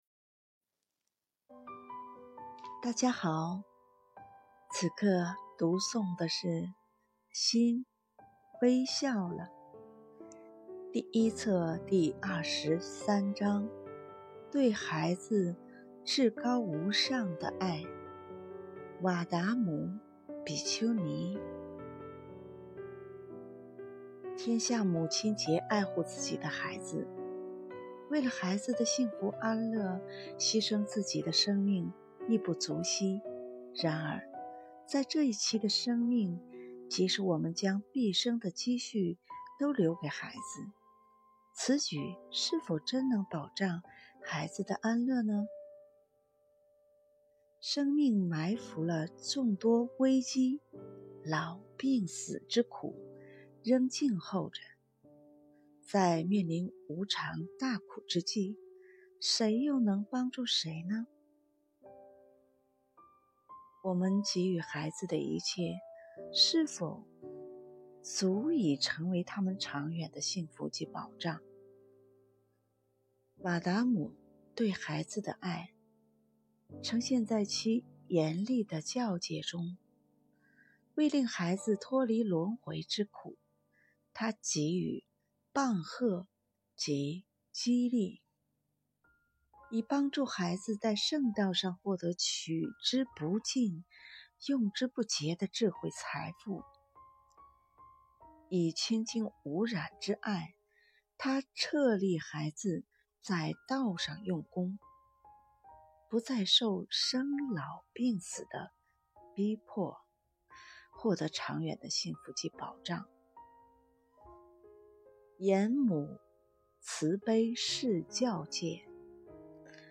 電子書： 《心微笑了》繁體版｜修訂版 PDF Download 《心微笑了》简体版｜修订版 PDF Download 有聲書： 對孩子至高無上的愛——瓦達母比丘尼 Footnotes 《長老尼偈》第204～206偈。